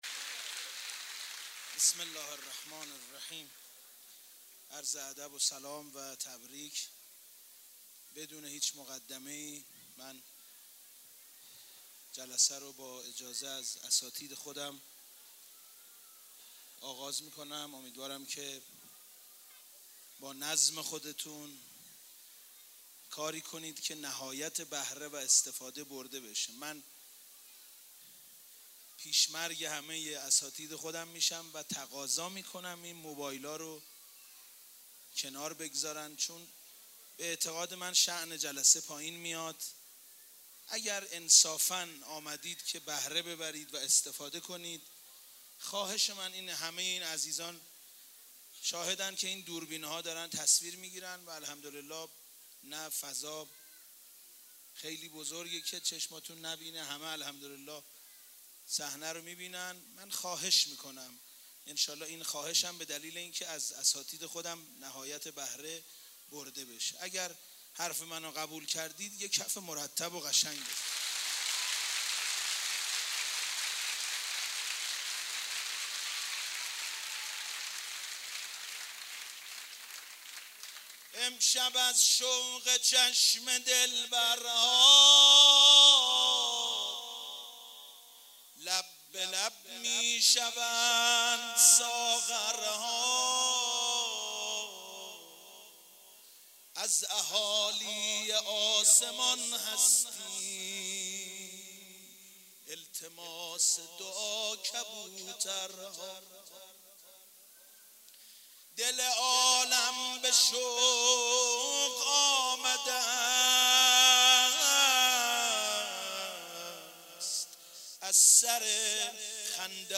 جشن بزرگ میلاد حضرت علی اصغر(ع)